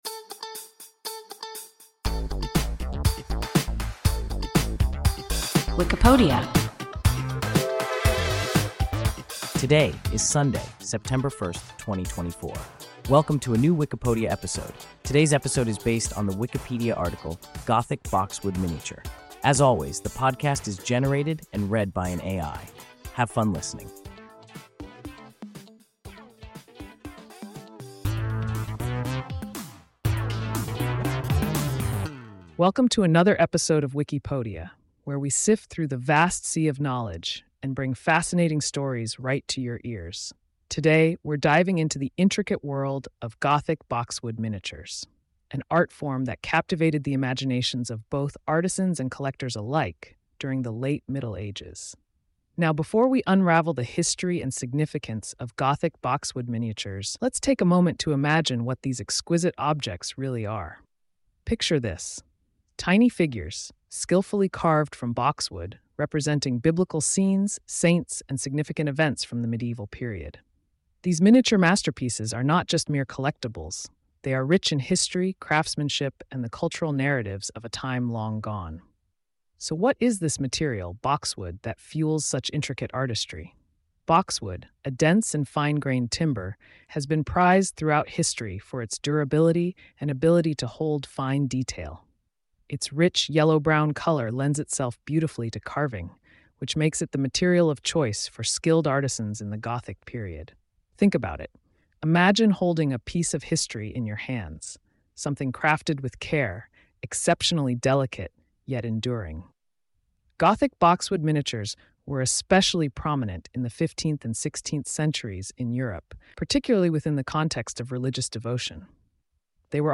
Gothic boxwood miniature – WIKIPODIA – ein KI Podcast